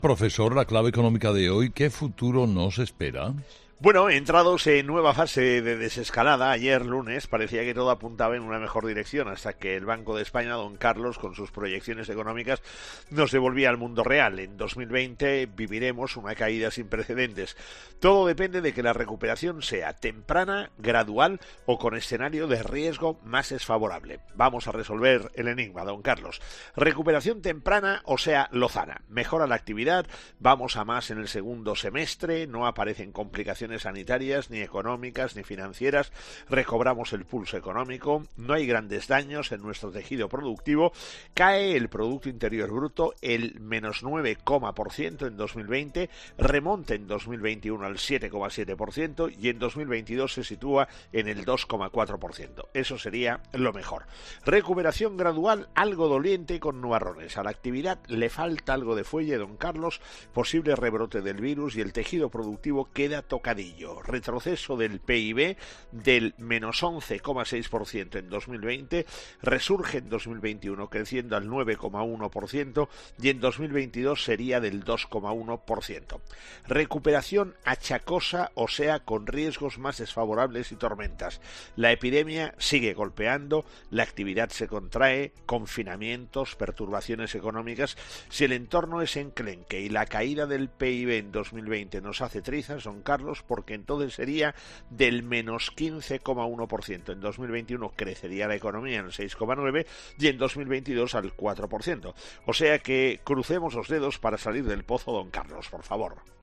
El profesor José María Gay de Liébanaanaliza en ‘Herrera en COPE’ las claves económicas del día